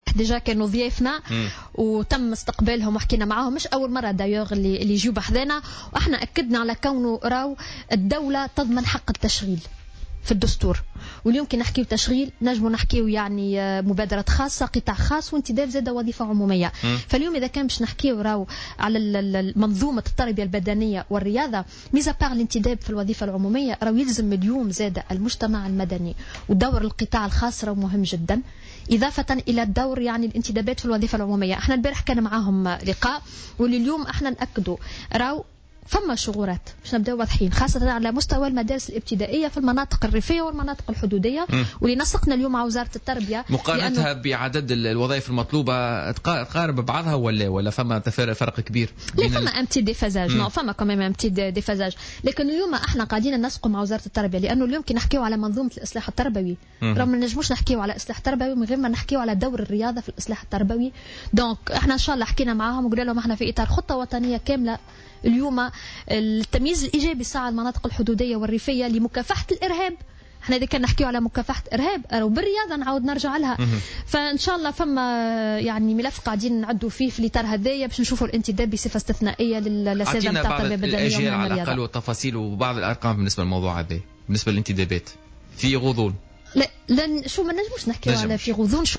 وأشارت الشارني، ضيفة برنامج "بوليتيكا" إلى وجود تنسيق بين وزارة الشباب والرياضة والتربية لسدّ هذه الشغورات.